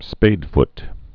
(spādft)